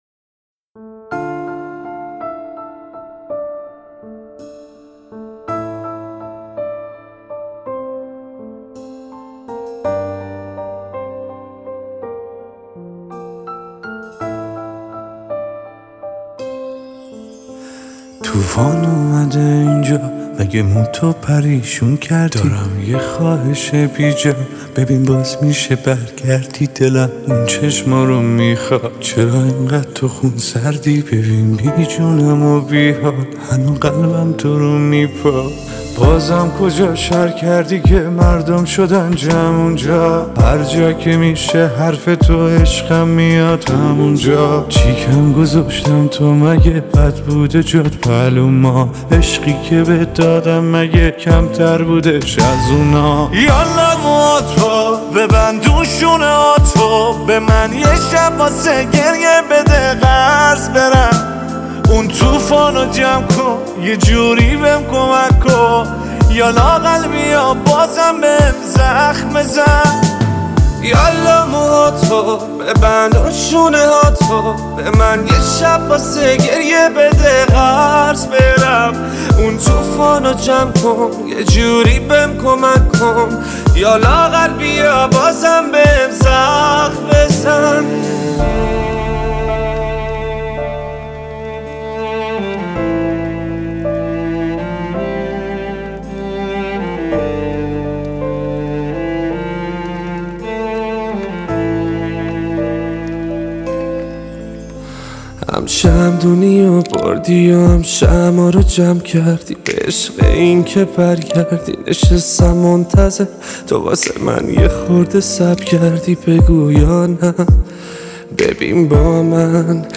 آهنگ چس ناله ای